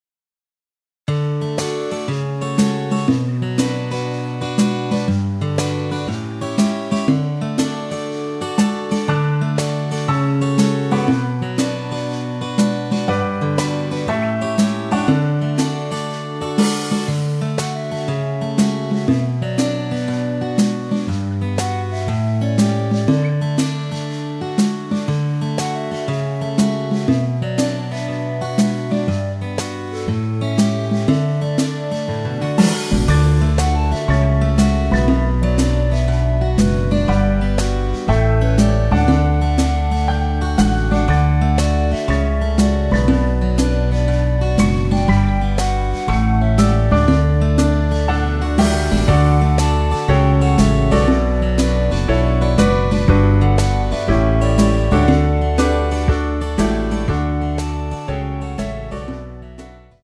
엔딩이 페이드 아웃에 너무 길어서 4마디로 엔딩을 만들었습니다.
원키에서(+2)올린 멜로디 포함된 MR 입니다.
Db
앞부분30초, 뒷부분30초씩 편집해서 올려 드리고 있습니다.